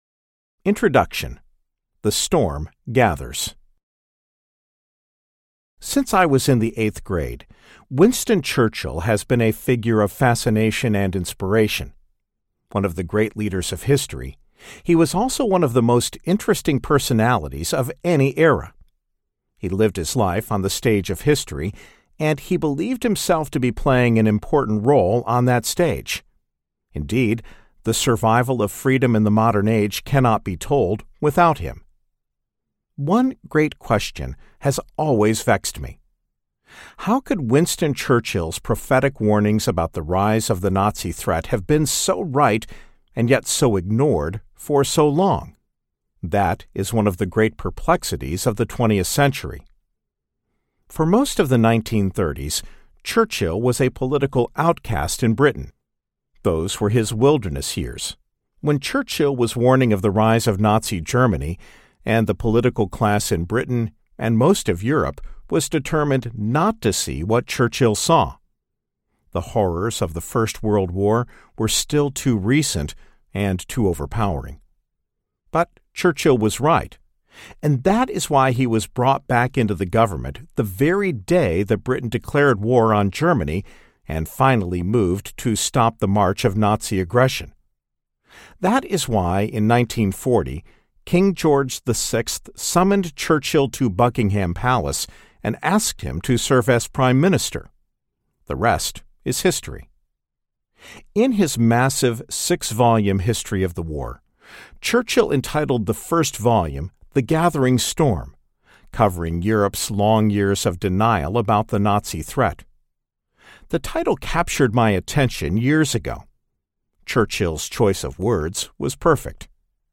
The Gathering Storm Audiobook
Narrator
7.5 Hrs. – Unabridged